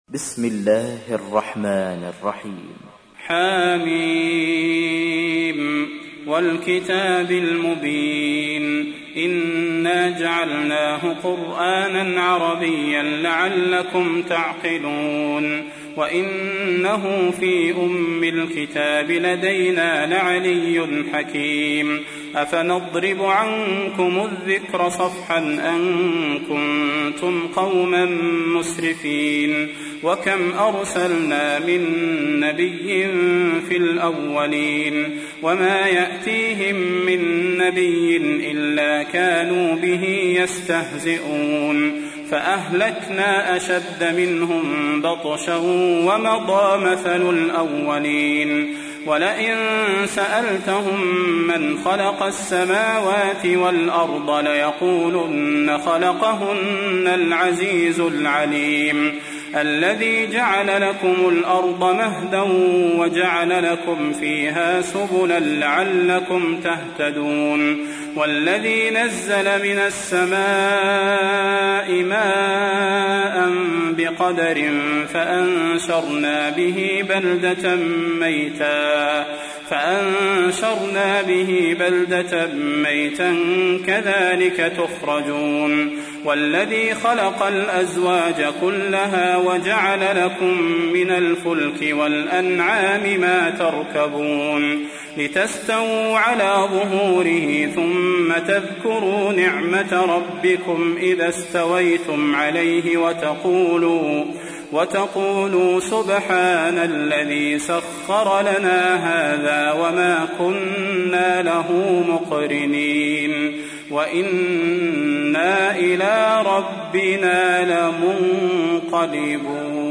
تحميل : 43. سورة الزخرف / القارئ صلاح البدير / القرآن الكريم / موقع يا حسين